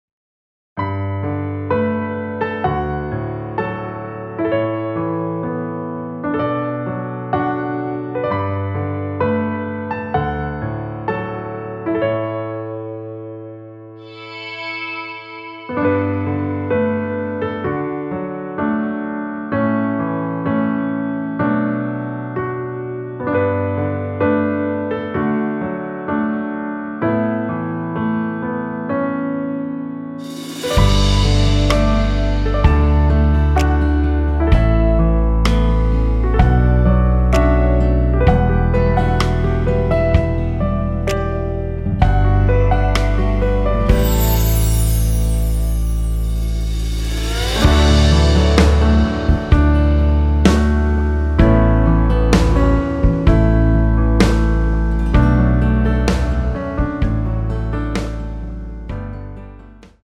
(-1) 내린 MR 입니다.
◈ 곡명 옆 (-1)은 반음 내림, (+1)은 반음 올림 입니다.
앞부분30초, 뒷부분30초씩 편집해서 올려 드리고 있습니다.